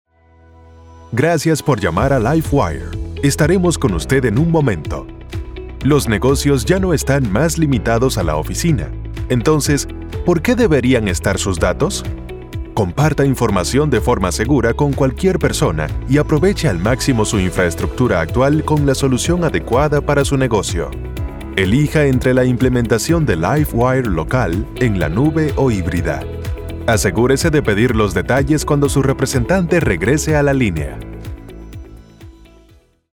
Male
Dominican Spanish (Native) Latin English (Accent) Neutral Latam Spanish (Native)
Elearning.mp3
Acoustically treated studio.